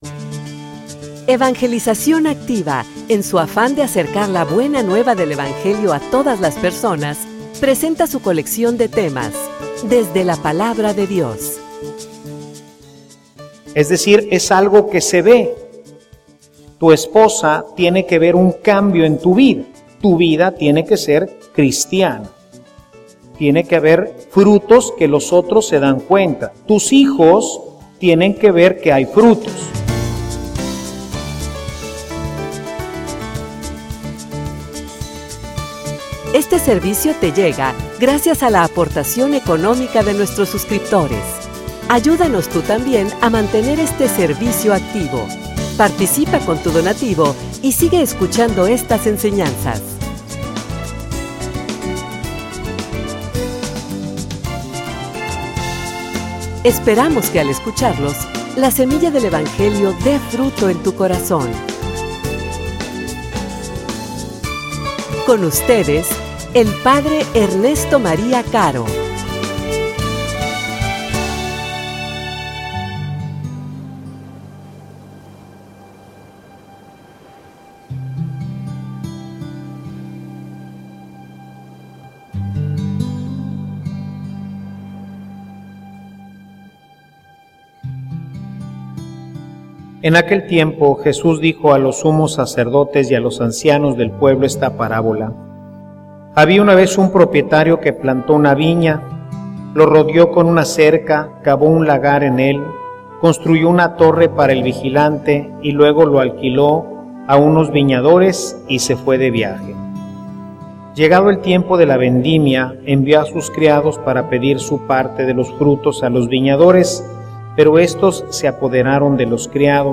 homilia_Que_mas_puede_hacer_por_ti.mp3